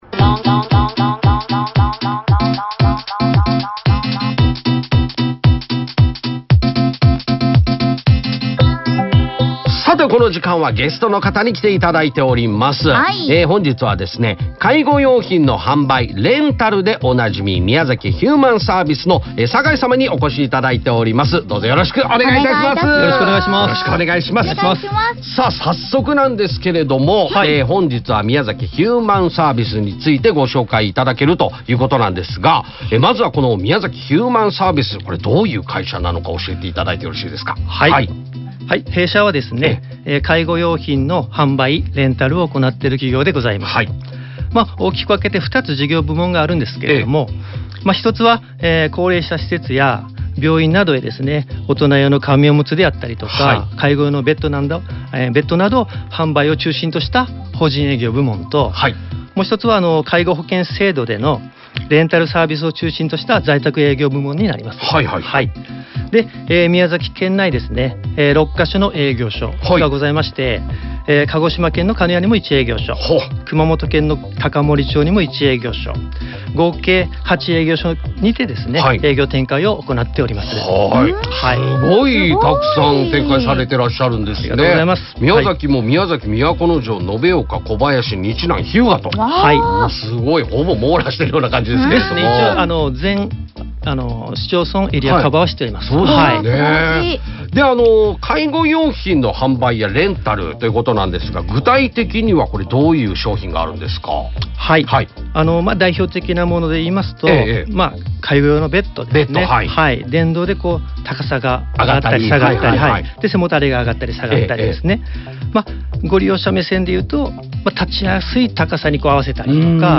耳恋ラジオに出演しました?